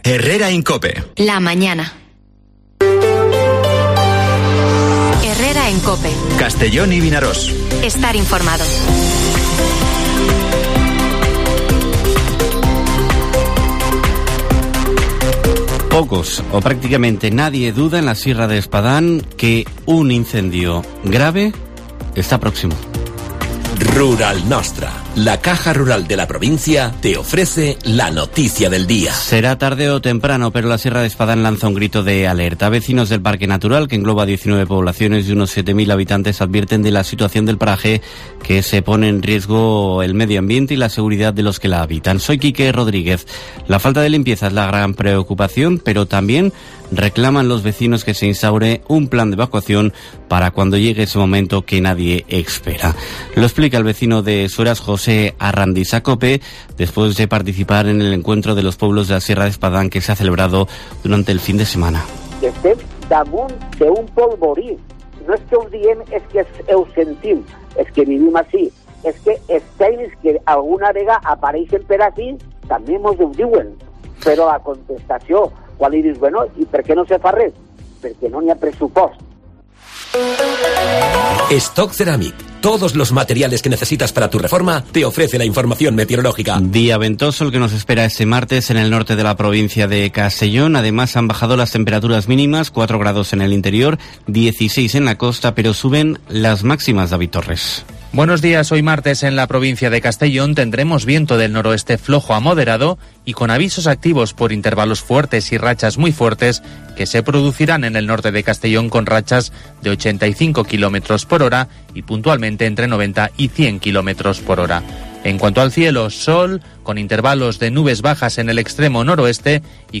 Informativo Herrera en COPE en la provincia de Castellón (27/09/2022)